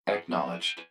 042_Acknowledged2.wav